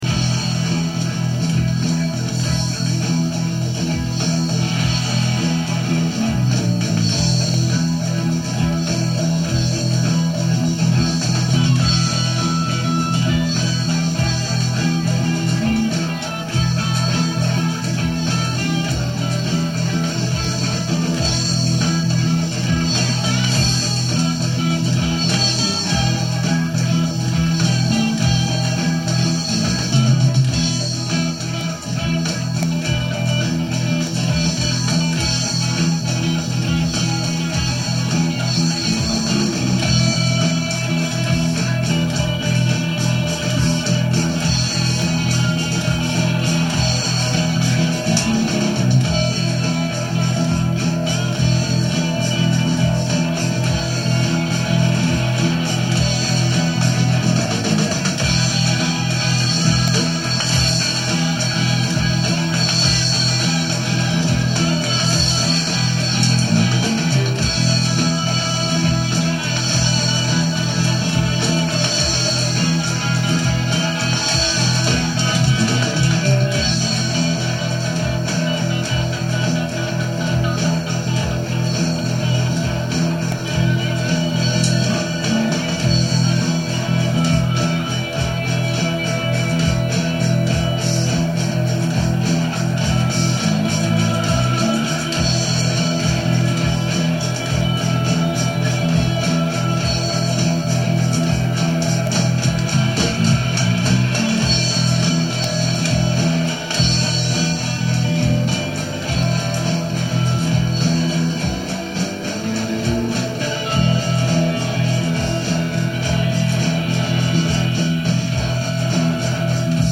guitar
drums
bass
E_jam.mp3